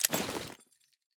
armor-open-3.ogg